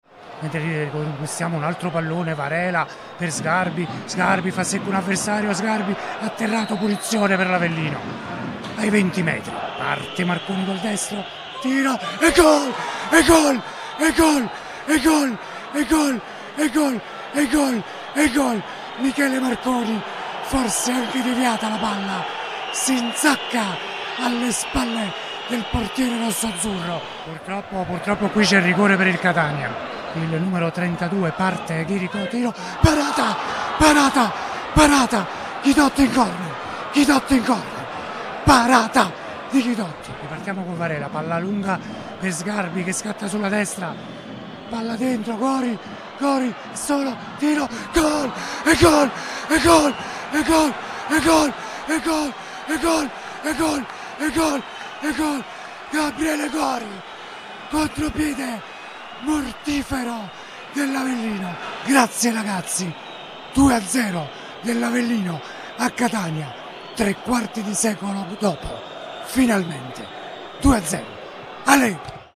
Gol Catania-Avellino 0-2 con la Radiocronaca